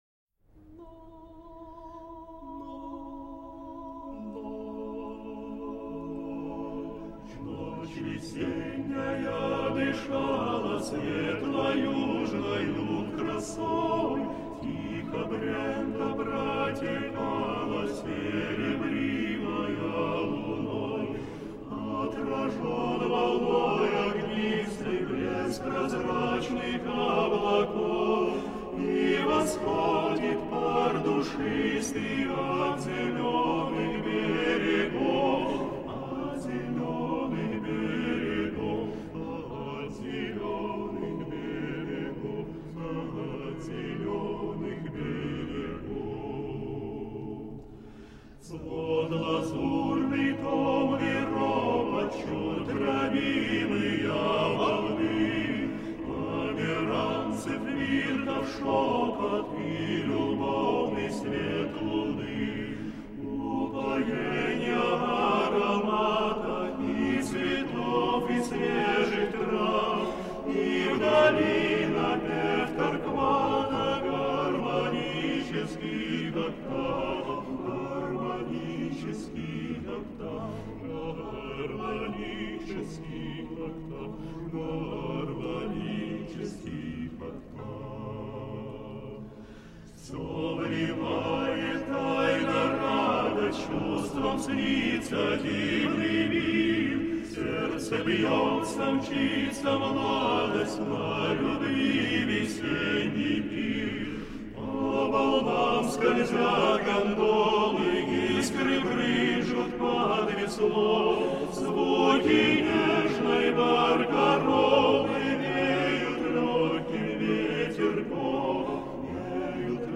мужского хора
хоровые сочинения русских композиторов